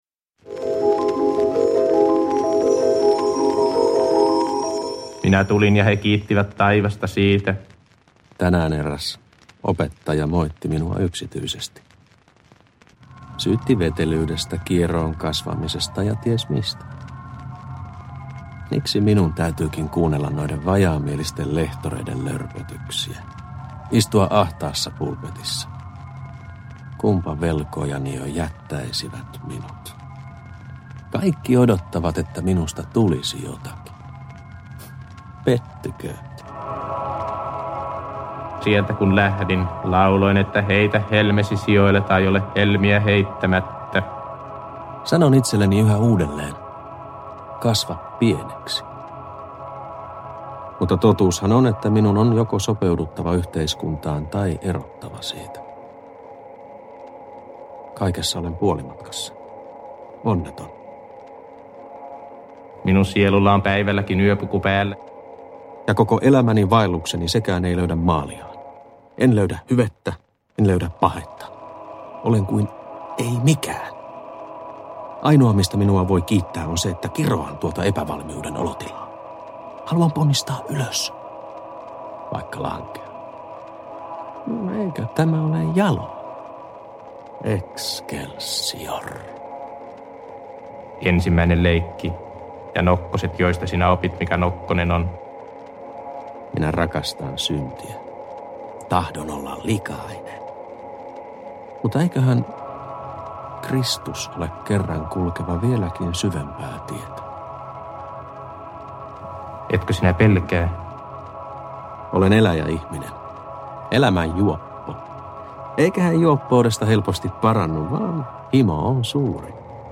Ääneen – Ljudbok – Laddas ner